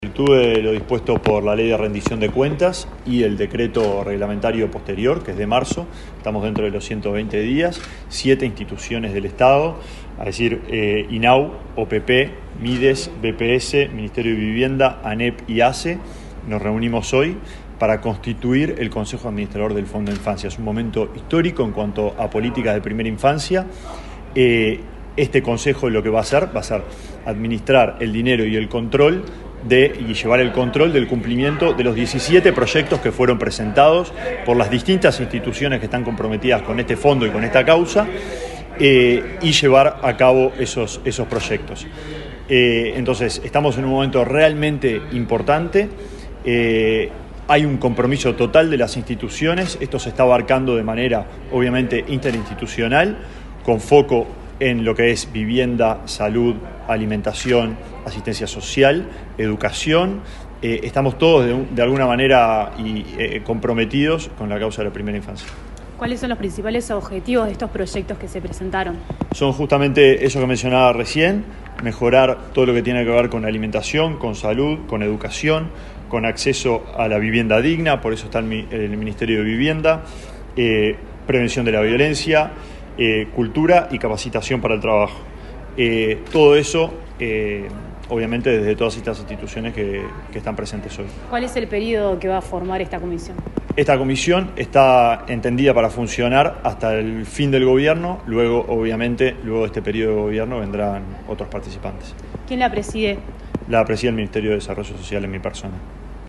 Declaraciones del ministro de Desarrollo Social, Alejandro Sciarra
El ministro de Desarrollo Social, Alejandro Sciarra, dialogó con Comunicación Presidencial, este martes 16 en la Torre Ejecutiva, luego de participar